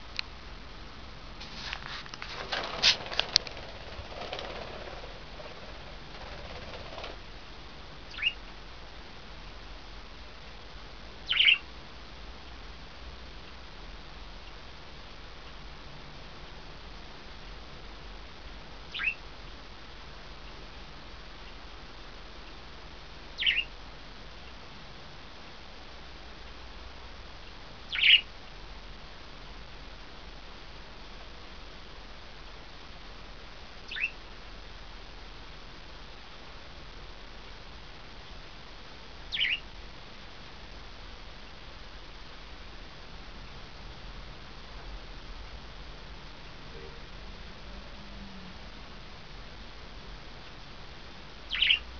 インコの羽音を聴いたことのない人は多いんじゃないかと思って録ってみたけど、
微かにしか入ってないわ…。
実際はすんごく力強い音なんですよ。
羽音(haoto)
さえずるばかりで飛ばなかった)